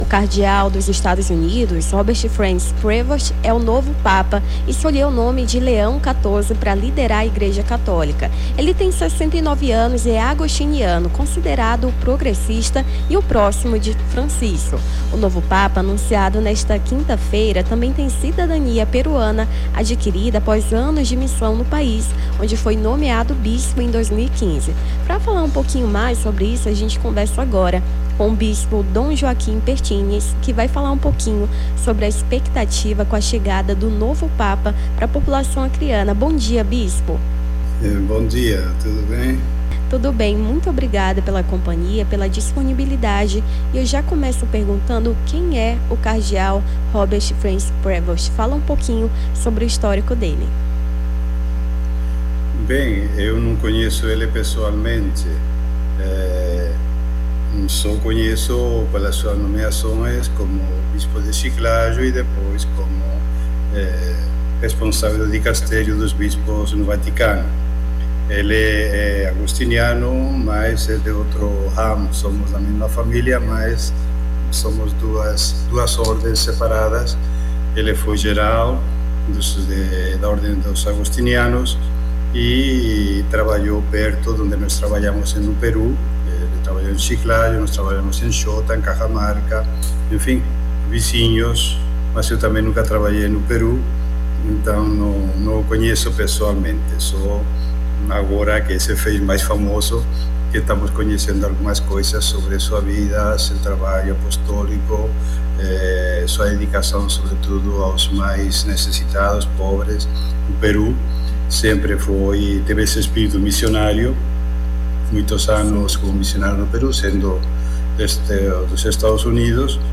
Nome do Artista - CENSURA - ENTREVISTA BISPO (09-05-25).mp3